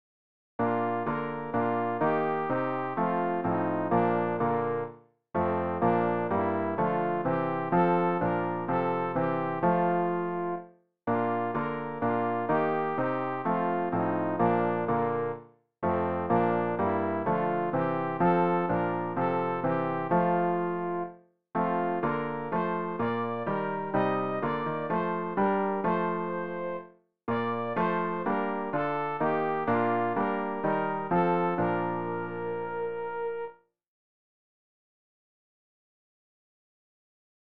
sopran-rg-243-dir-dir-jehova-will-ich-singen.mp3